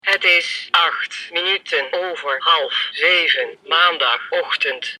Deze sleutelhanger heeft een helder geluid van hoogwaardige kwaliteit.
Deze klok kan de tijd zowel digitaal als analoog voorgelezen worden, net als bij de DianaTalks horloges.
My_Time_Dutch_analog.mp3